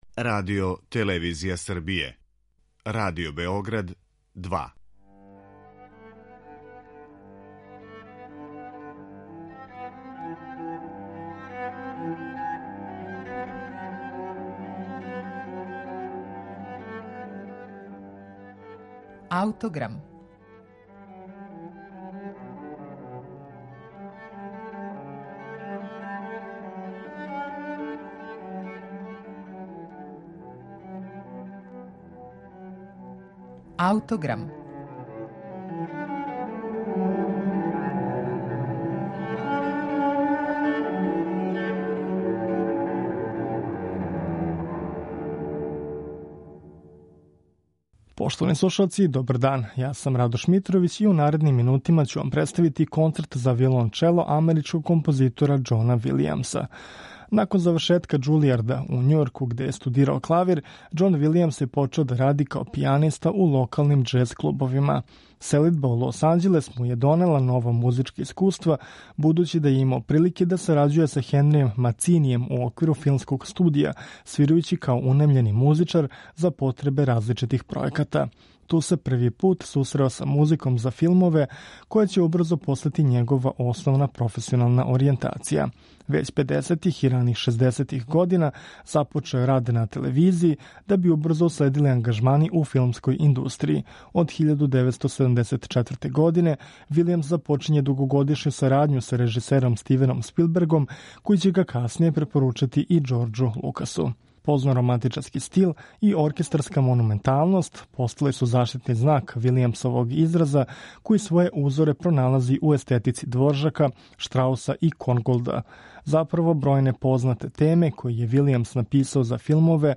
Реч је о извођењу уживо из 2012. године.